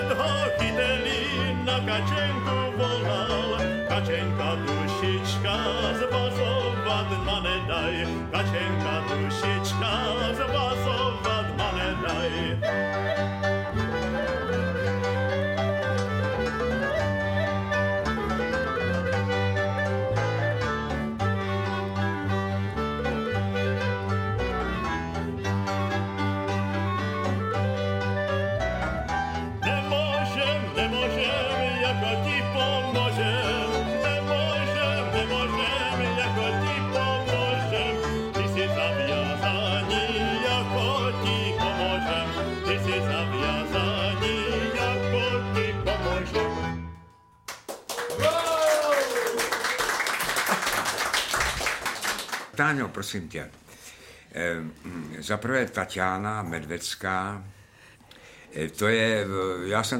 Audiobook
Audiobooks » Humour, Satire & Comedy